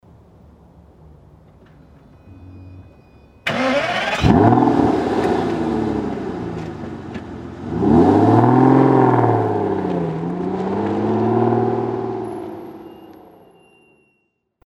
Whilst you save up to be able to afford one of these why not visit the Aston website or listen to the engine by